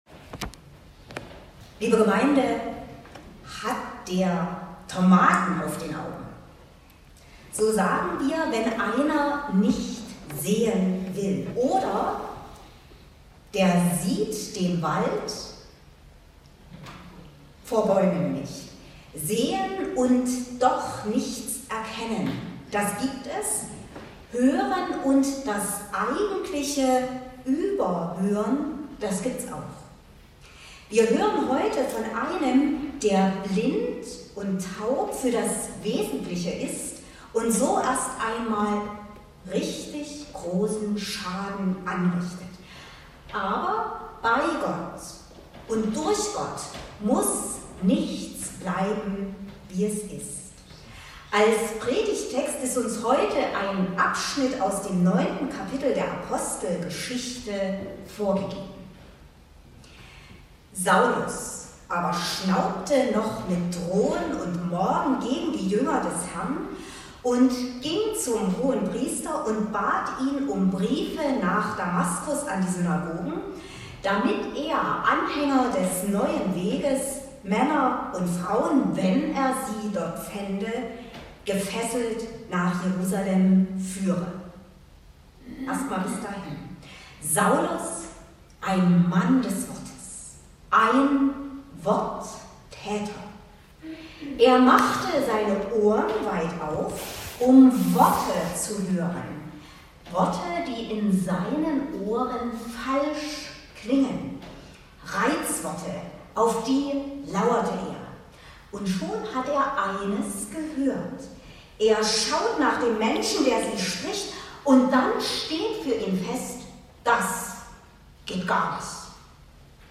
Passage: Apostelgeschichte 9;1-20 Gottesdienstart: Predigtgottesdienst Wildenau « Der andere verlorene Sohn…